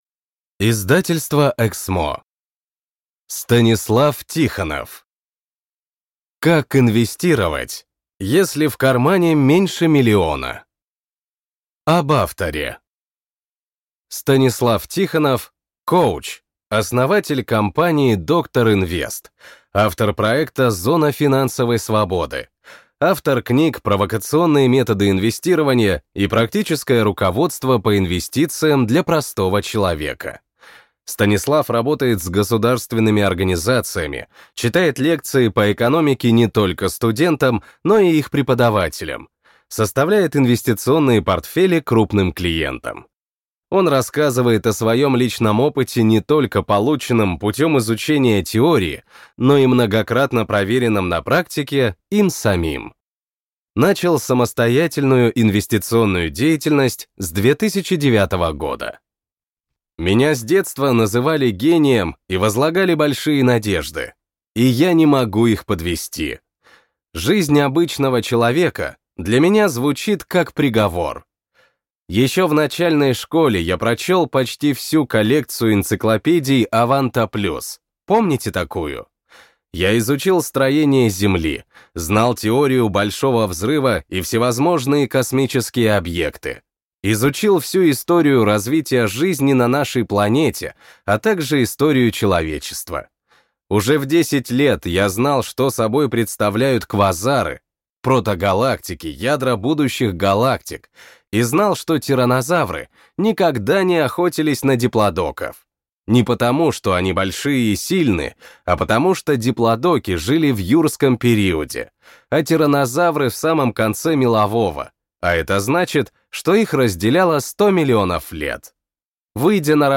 Аудиокнига Как инвестировать, если в кармане меньше миллиона | Библиотека аудиокниг